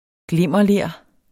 Udtale [ ˈglemˀʌˌleˀɐ̯ ]